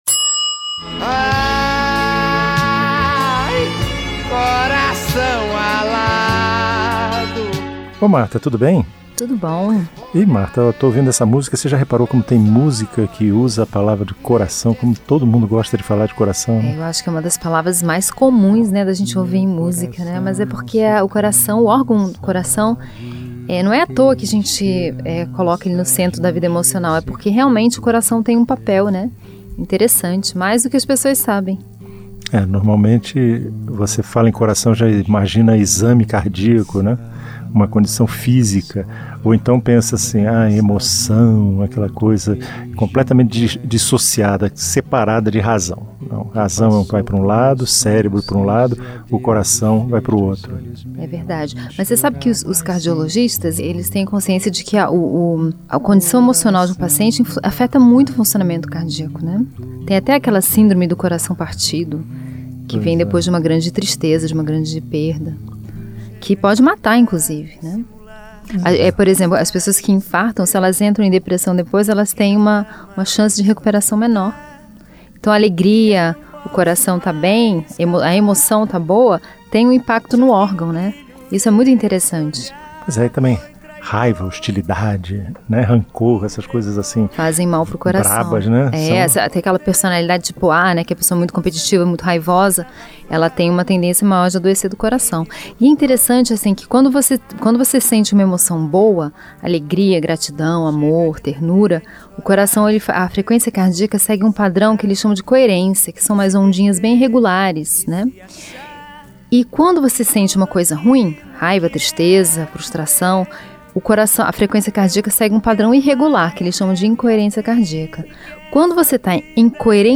O Conversa de Elevador é um programa de bate-papo sobre temas variados, e que pretende ser ao mesmo tempo leve, breve e divertido, sem deixar de provocar uma reflexão no ouvinte.
Enfim, é uma conversa solta e sem compromisso, marcada pelas experiências do dia-a-dia e pela convivência em um mundo que às vezes parece tão rápido e tão cheio de subidas e descidas quanto um elevador.